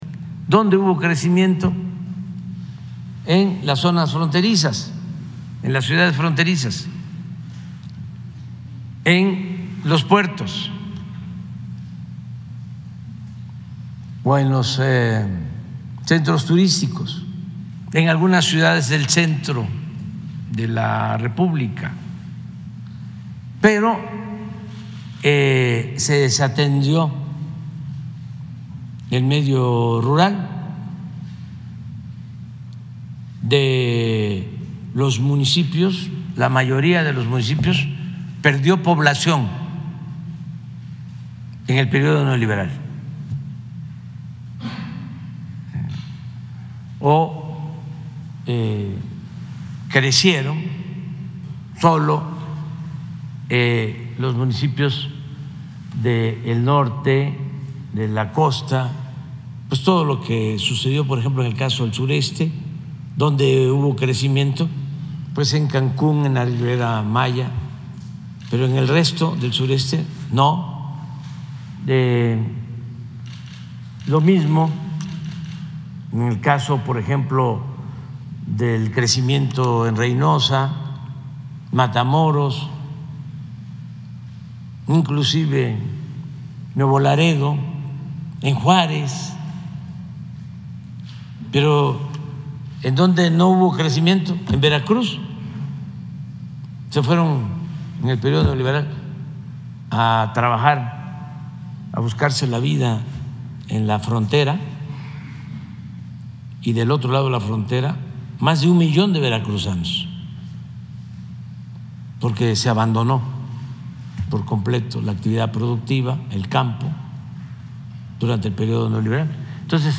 AUDIO: PRESIDENTE ANDRÉS MANUEL LÓPEZ OBRADOR, SOBRE DESIGUAL DESARROLLO REGIONALES
Ciudad de México.- Durante su tradicional conferencia matutina «Mañanera», desde Palacio Nacional, el presidente, Andrés Manuel López Obrador, mencionó que en el periodo neoliberal se privilegió el desarrollo de las ciudades fronterizas y puertos, así como los centros turísticos y mencionó a Cd. Juá